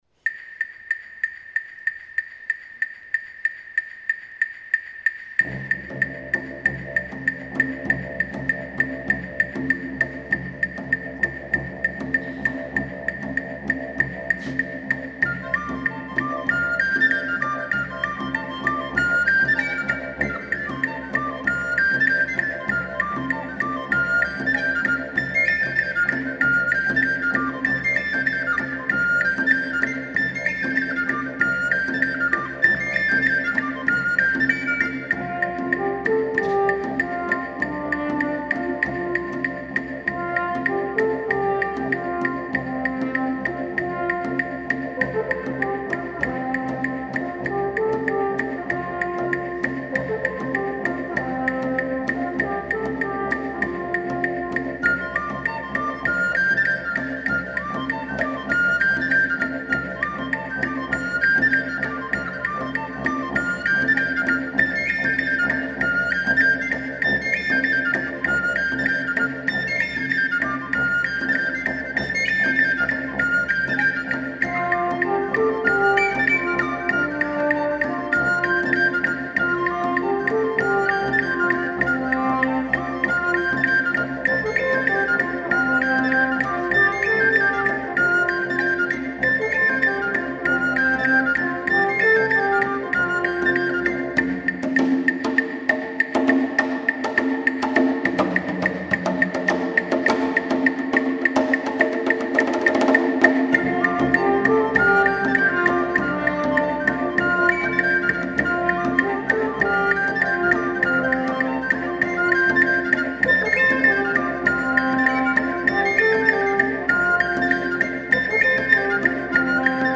Alpendidg.
Waldhorn
Percussion
Flöte